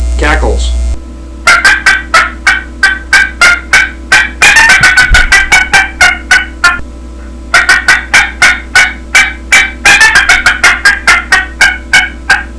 Listen to 13 seconds of cackles
• Makes medium-toned and raspy yelps, cackles, clucks, and cutts at all volume levels.
qbgroldbosshencackles13.wav